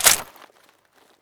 Index of /server/sound/weapons/fnscarh
aug_clipin.wav